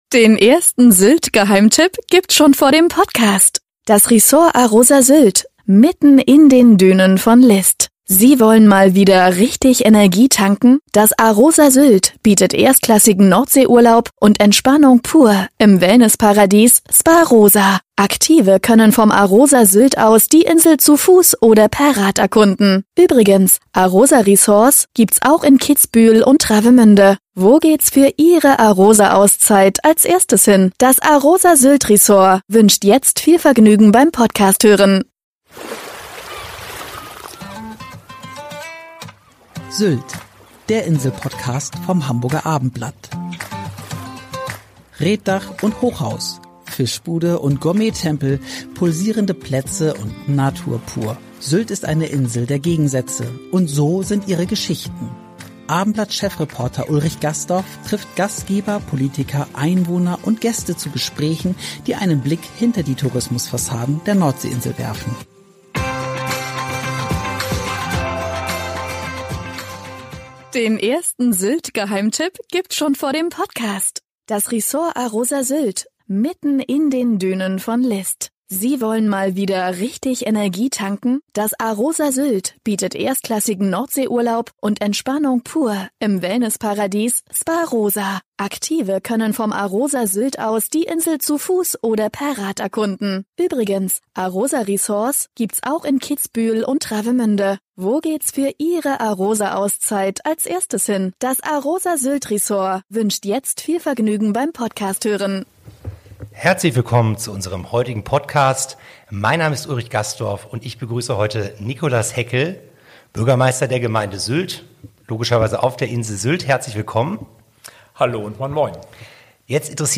Ein Gespräch mit Bürgermeister Nikolas Häckel über neue Regeln für Autofahrer, den Bahnärger und bezahlbaren Wohnraum.